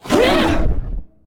combat / creatures / ryuchi / she / attack2.ogg
attack2.ogg